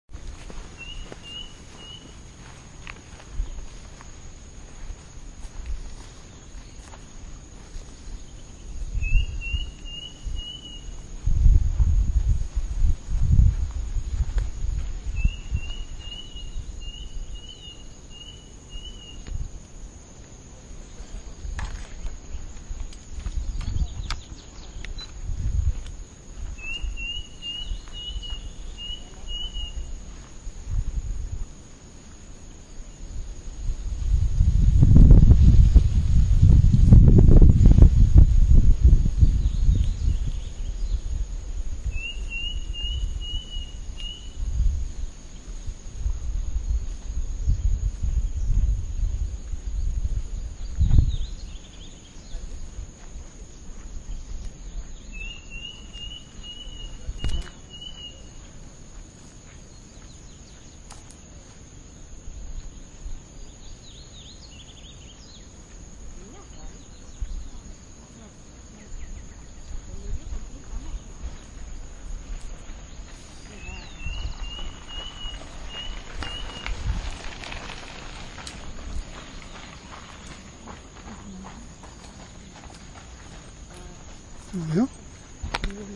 Striped Cuckoo (Tapera naevia)
Life Stage: Adult
Location or protected area: Reserva Ecológica Costanera Sur (RECS)
Condition: Wild
Certainty: Recorded vocal